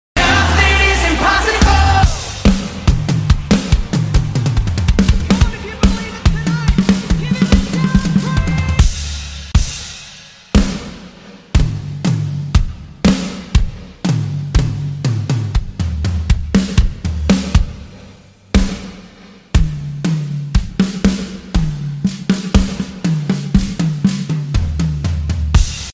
Nothing is Impossible: Solo Drum